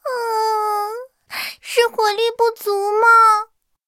BT-2中破语音.OGG